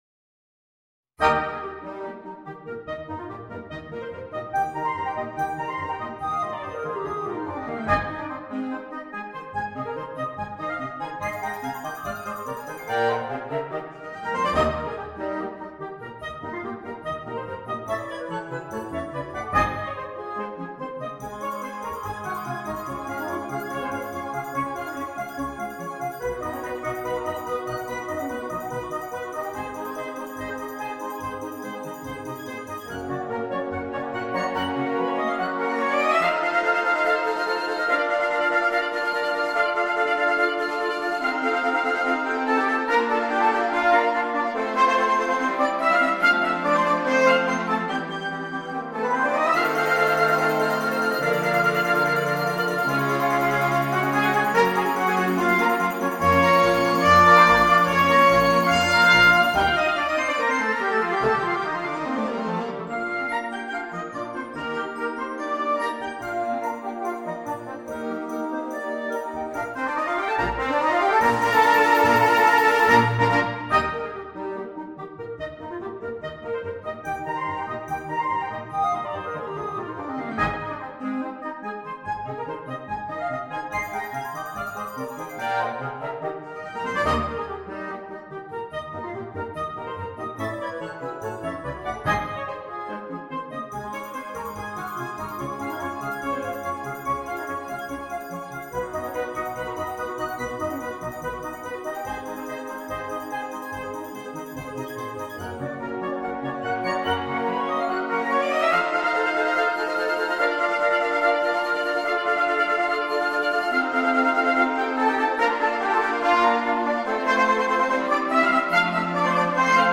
Konzertwerk für Blasorchester
Besetzung: Blasorchester